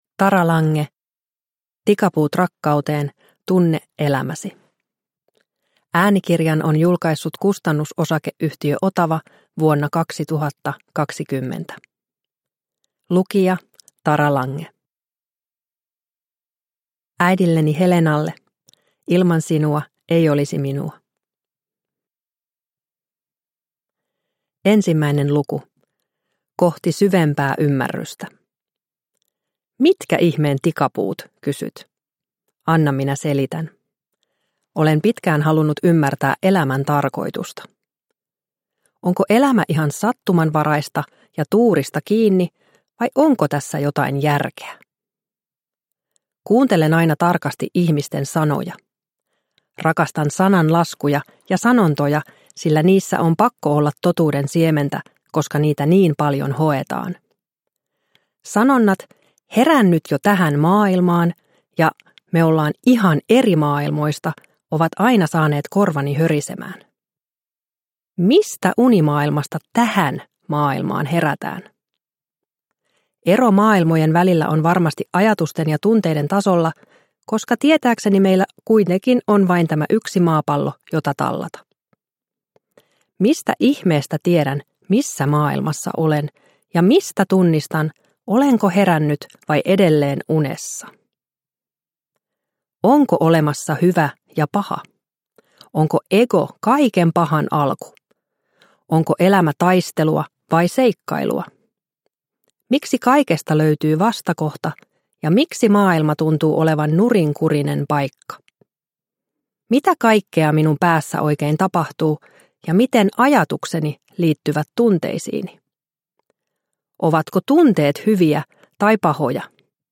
Tikapuut rakkauteen – Ljudbok – Laddas ner